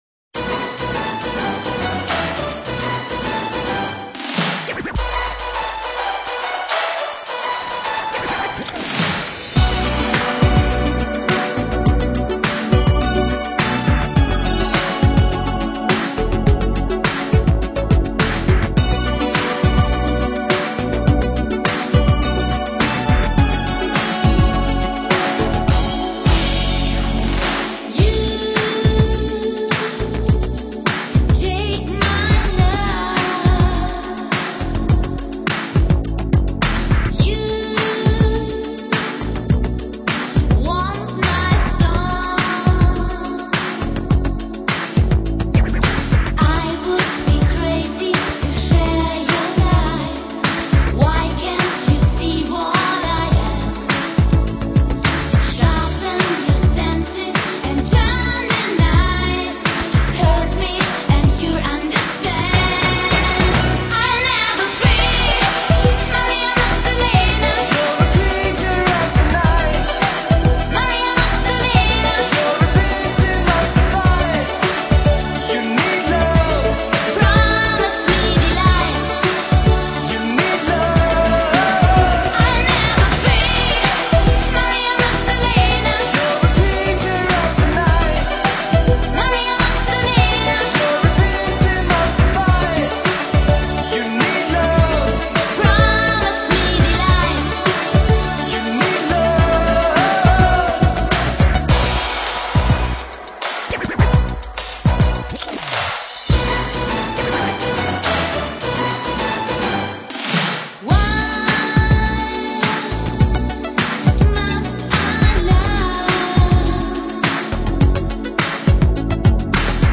Жанры: синти-поп, танцевальная музыка,
евродиско, поп-рок, евродэнс